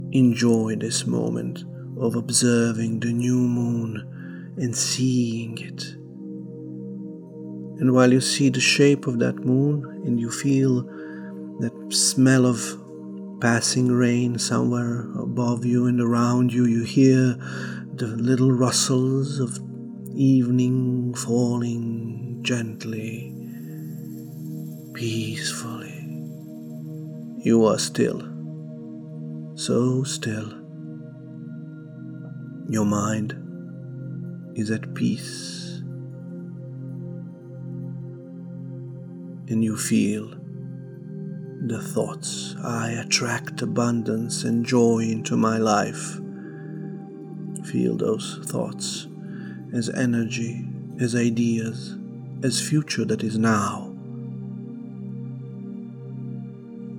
New Moon River session sample:
a minute of atmosphere and tone of the session - get a pen and paper, and set the intentions for the coming period.